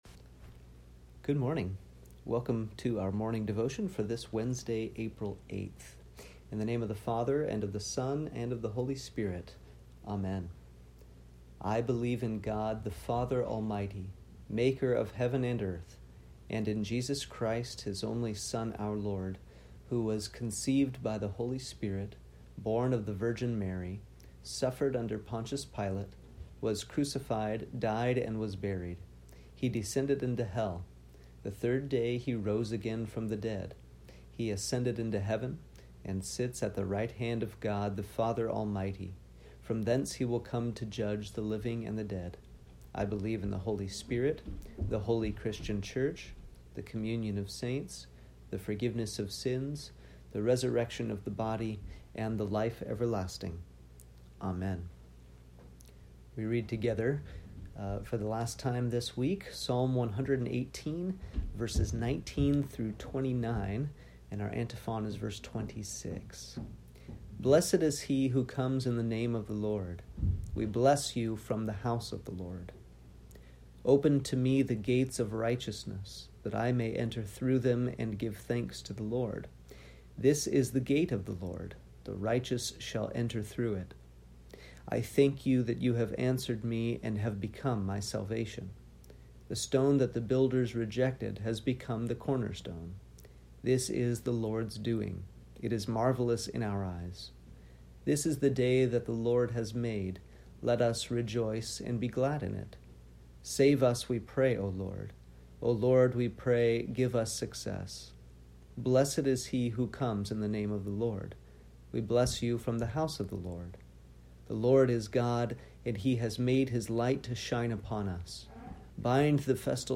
Morning Devotion for Wednesday, April 8th